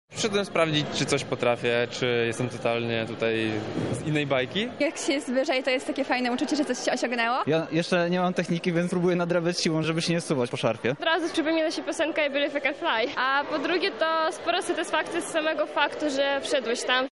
Swoimi wrażeniami z warsztatów podzielili się z nami ich uczestnicy.